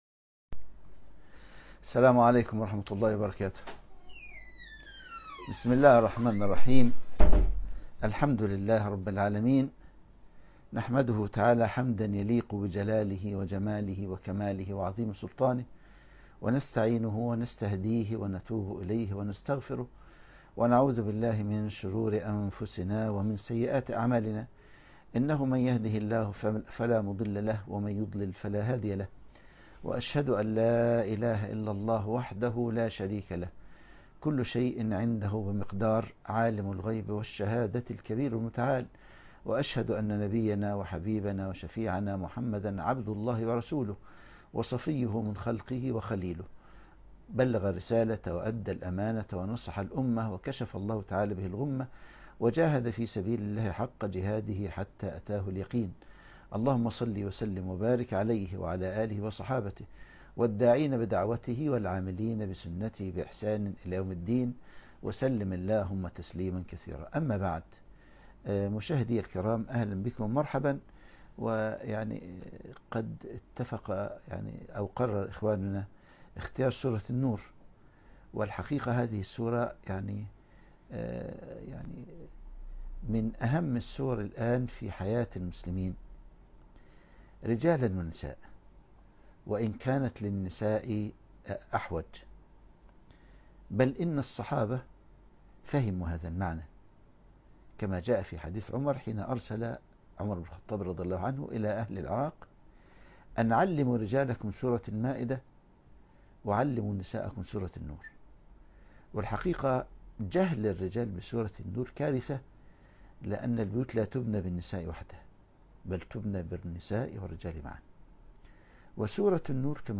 بث مباشر تفسير سورة الأحزاب